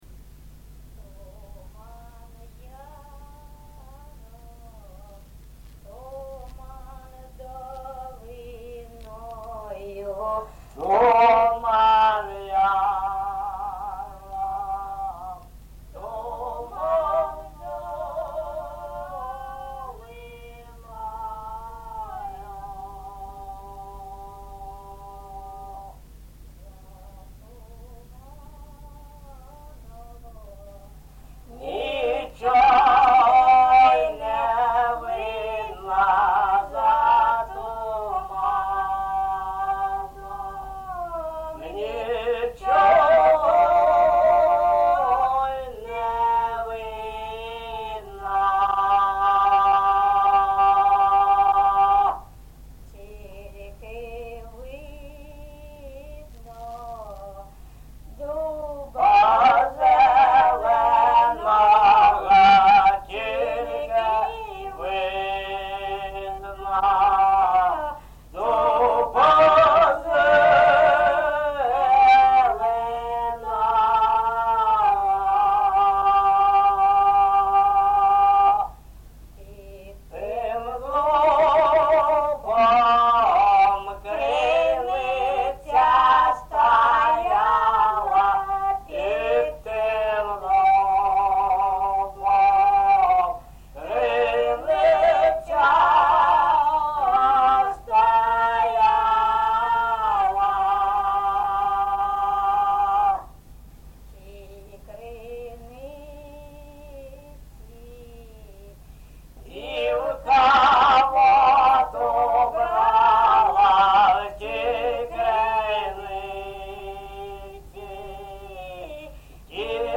ЖанрПісні з особистого та родинного життя
Місце записус-ще Троїцьке, Сватівський район, Луганська обл., Україна, Слобожанщина